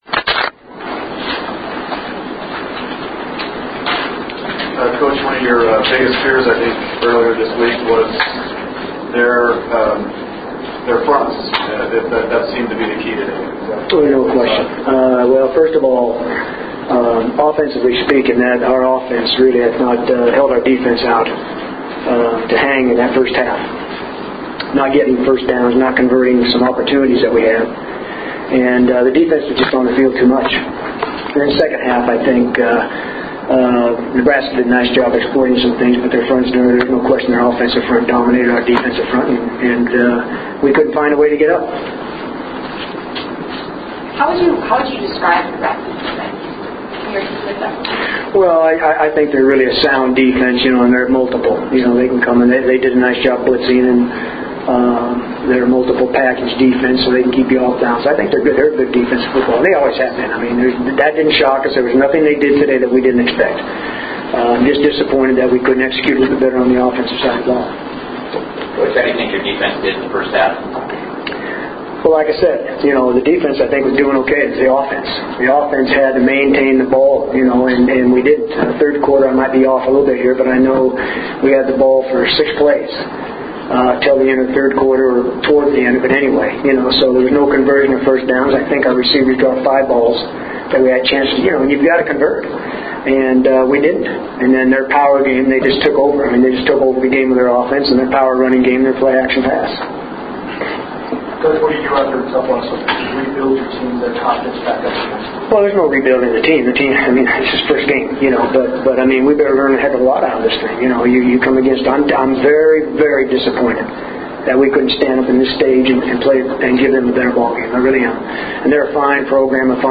Nevada Coach Chris Ault Quotes (
Postgame Audio)